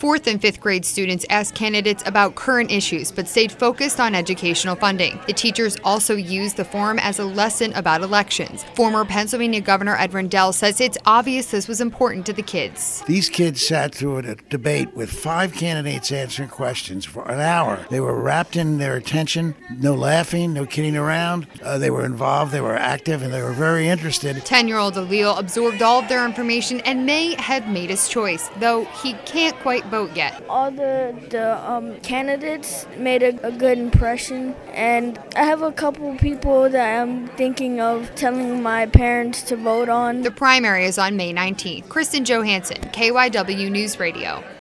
The Rendell Center also sponsored a youth mayoral forum where 4th and 5th graders could question Philadelphia's mayoral candidates.